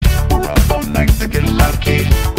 Categoria Elettronica